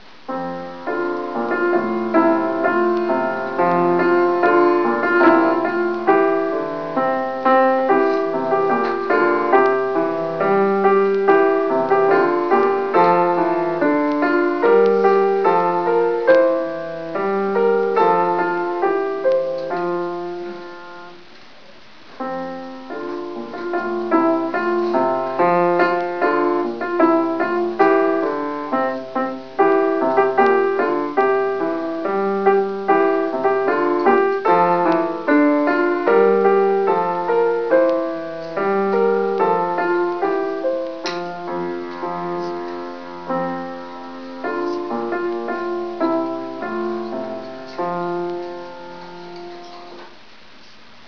29 september 2000: Live opnames gemaakt vanuit de coulissen tijdens het Open podium in de Gaviolizaal te Helmond. Zang
Piano